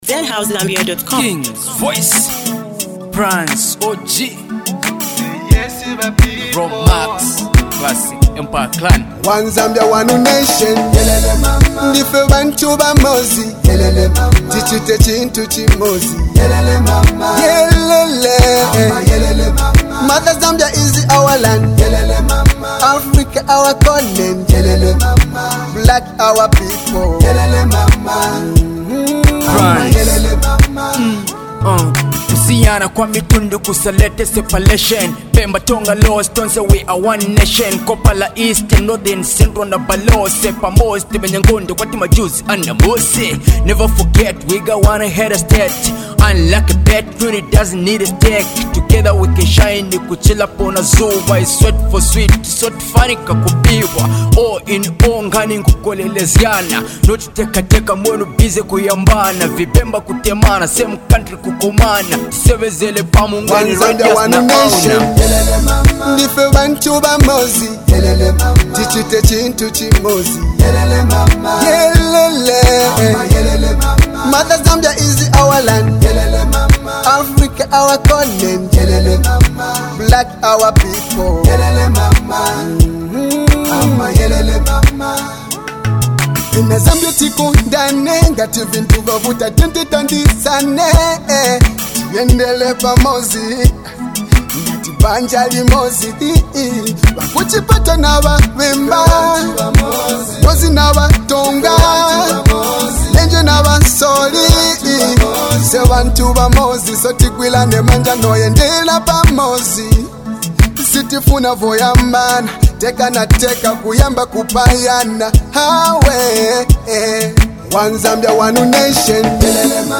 is a powerful anthem of unity, peace, and patriotism.